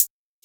Closed Hats
hihat 8.wav